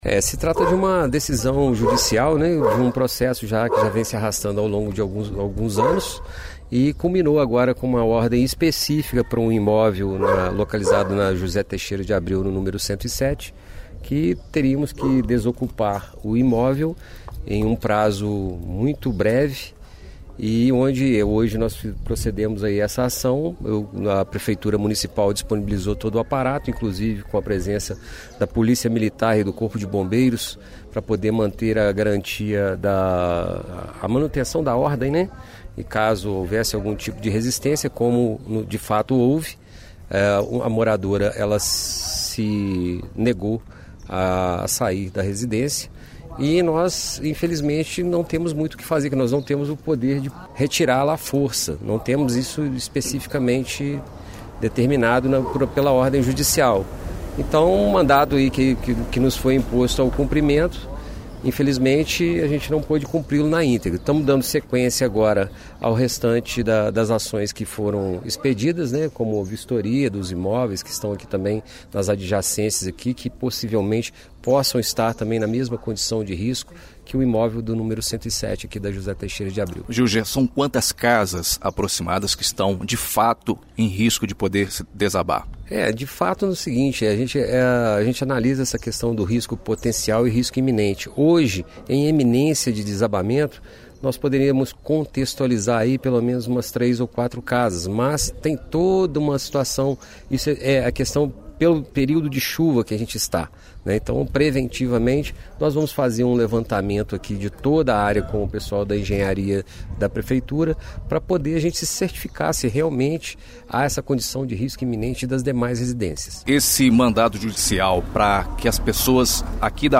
ENTREVISTA COORDENADOR DEFESA CIVIL – RÁDIO EDUCADORA AM/FM